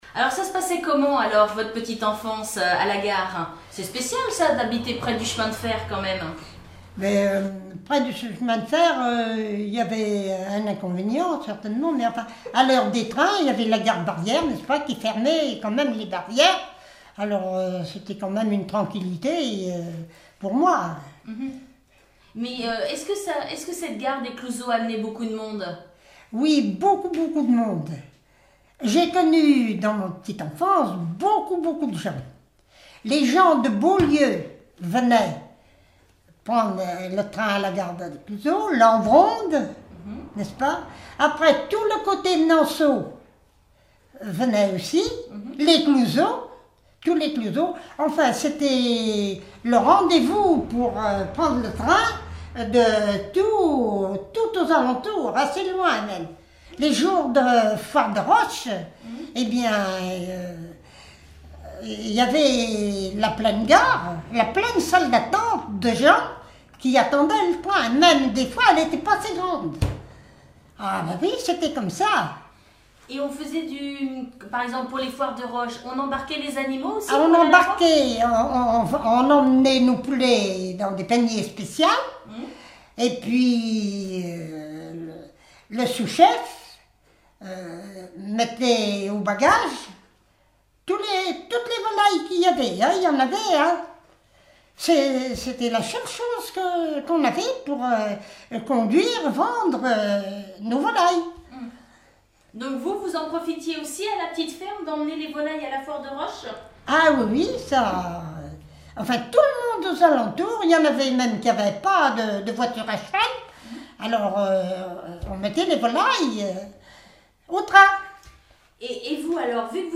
Témoignages sur la vie domestique